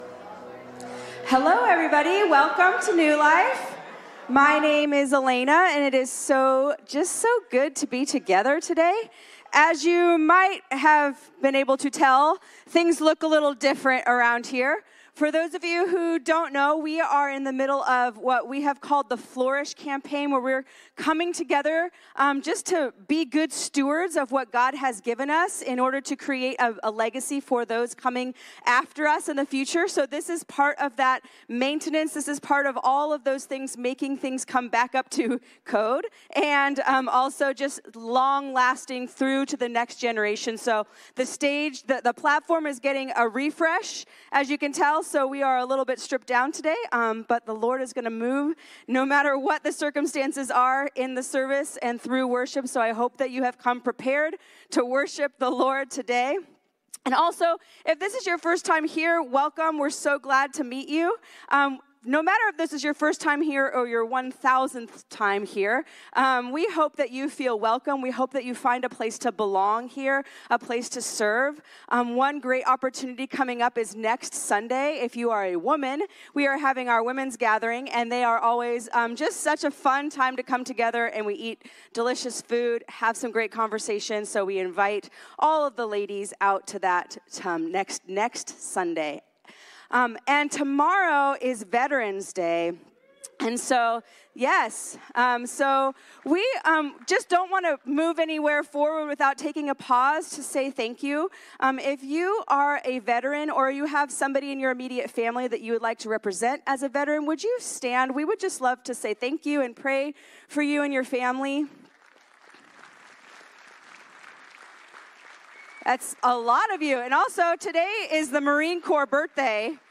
Sermons - N E W L I F E